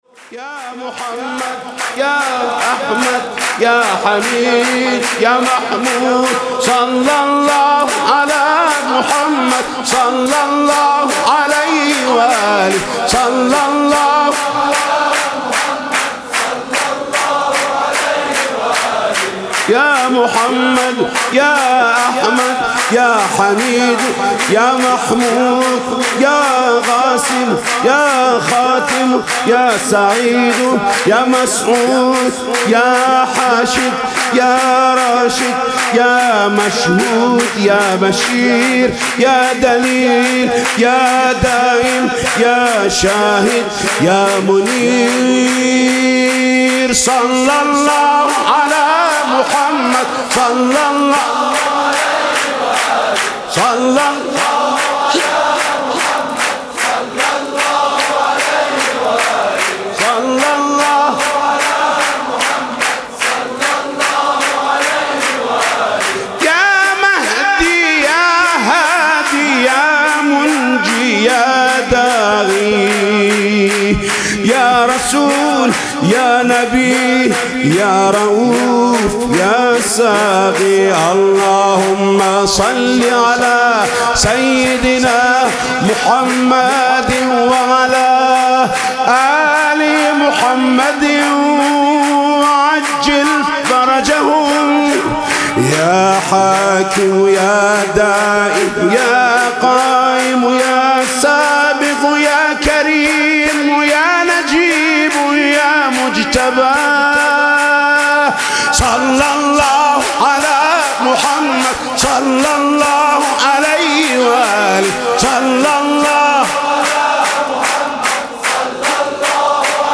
سرود عربی میلاد پیامبر و امام صادق (علیهم السلام)